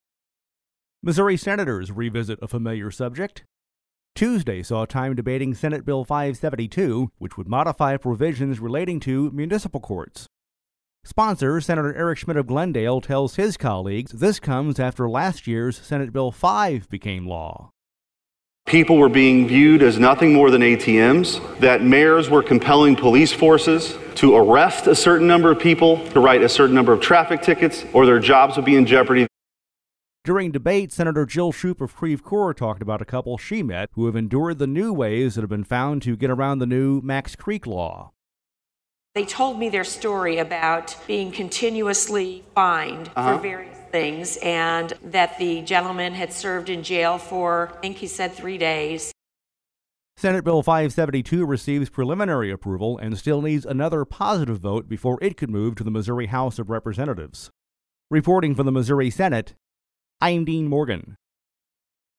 We’ve included actualities from Sen. Eric Schmitt, R-Glendale, and Sen. Jill Schupp, D-Creve Coeur